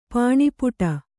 ♪ pāṇipuṭa